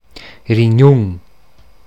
Ladinisch-mundartliche Form
[riˈɲuŋ]
Gadertaler Variante.
Rignùn_Mundart.mp3